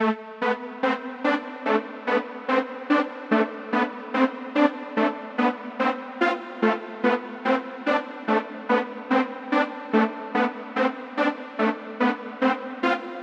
描述：在Fl studio 12中用sylenth制作的
Tag: 145 bpm Trap Loops Synth Loops 2.23 MB wav Key : A